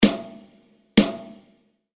Drum-Tuning
Satter, konkreter Sound mit ausgeprägtem Obertonspektrum
Mit genügend Volumen, aber ohne übermässigen Bassanteil eignet sich der Sound besonders für das weite Feld der Pop-/Rock-/Fusion-Musik, da er sich schon durch geringe Variationen in Stimmung und Dämpfung anpassen lässt.
Die so gestimmte Snaredrum wird nun noch entsprechend leicht gedämpft.
dt_snr02.mp3